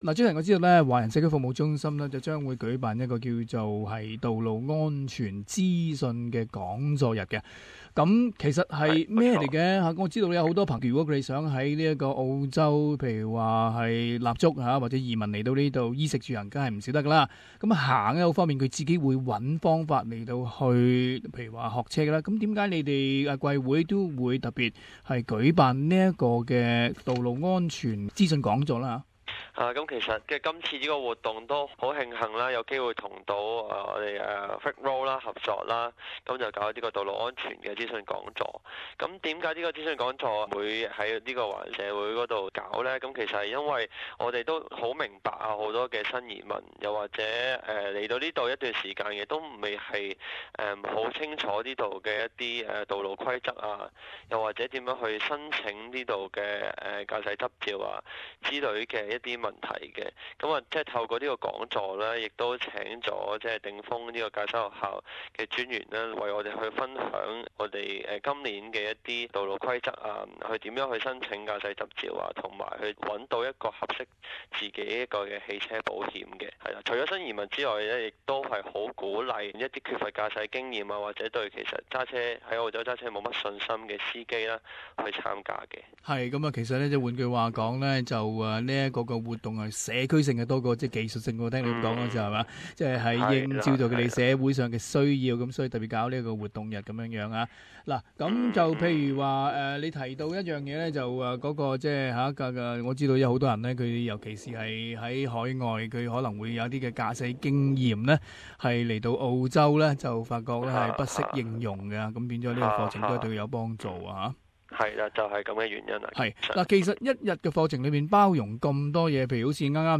【社團專訪】 道路安全資訊日